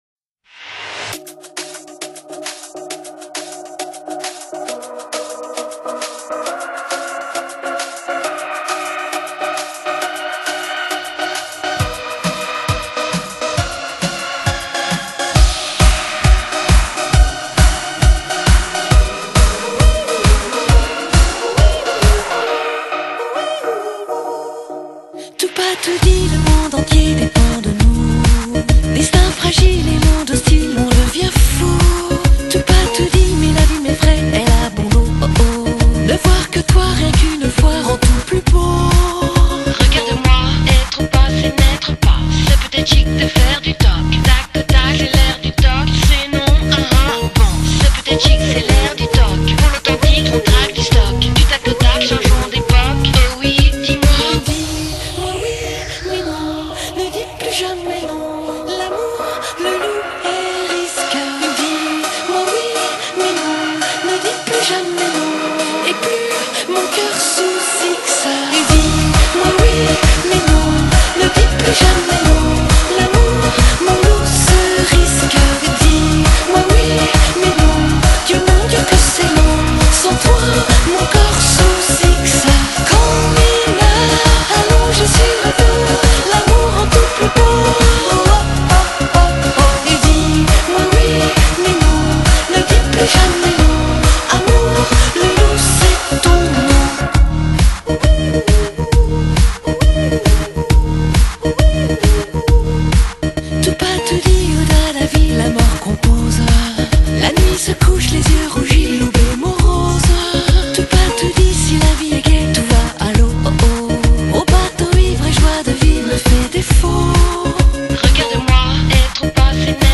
Genre: Pop
她的歌声另类、却富亲和力，穿透力极强，宛如天籁。